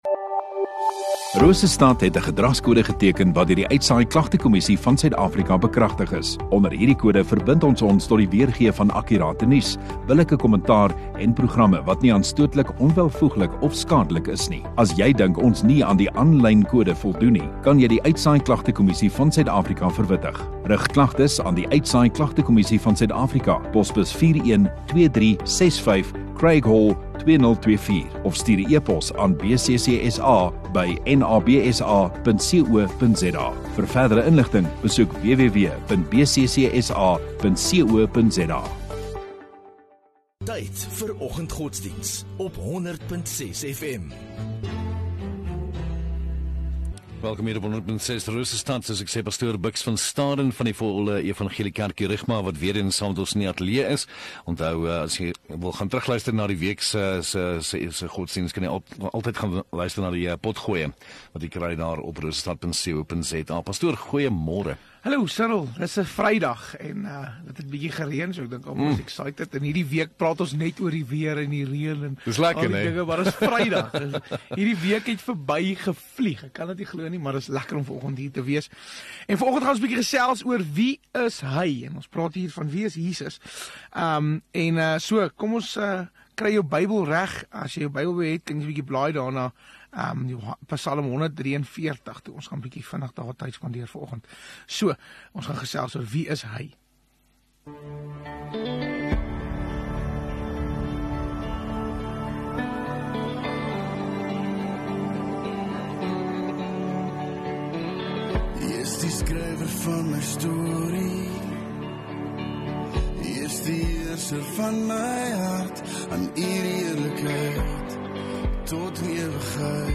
13 Oct Vrydag Oggenddiens